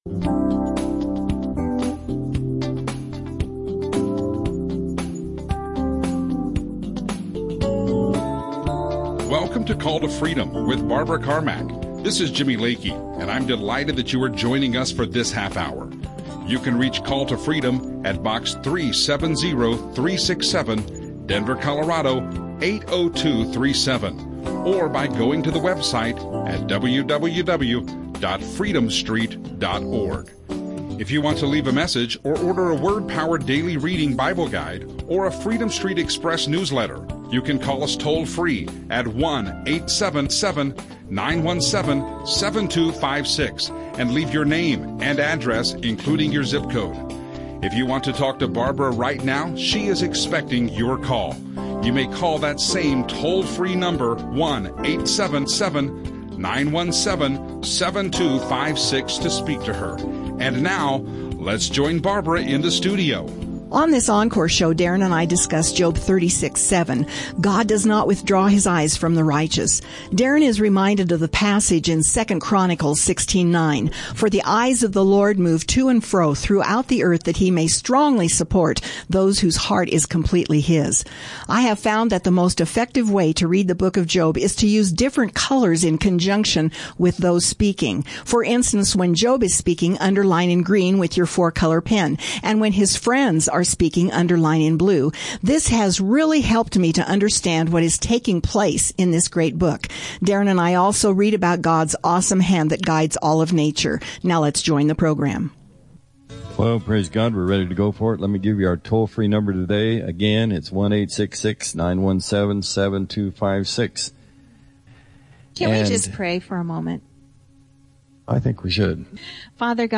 Biblical teaching
Christian radio